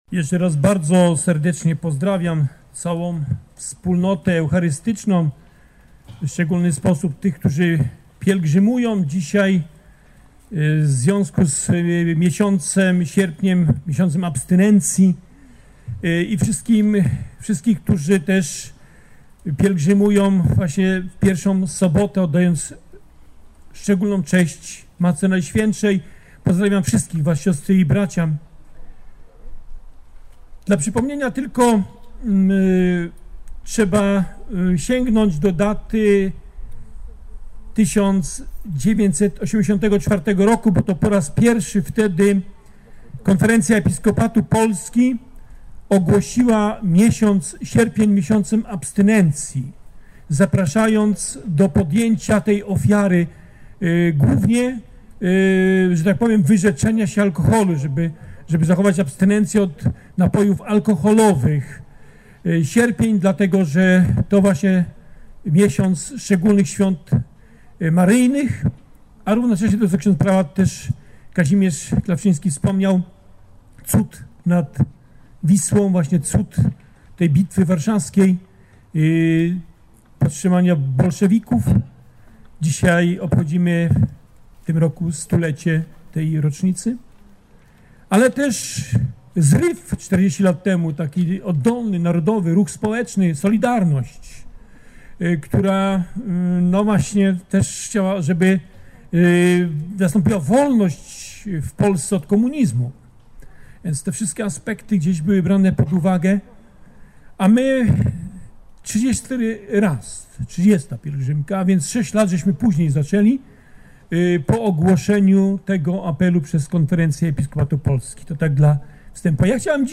Mszy św. przy Sanktuarium Matki Bożej Trzykroć Przedziwnej w samo południe przewodniczył bp Krzysztof Włodarczyk.